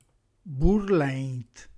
Qui di seguito il nostro repertorio delle parole “reggiane” proprie del nostro dialetto, sia per vocabolo che per significato ad esso attribuito, corredate della traccia audio con la dizione dialettale corretta.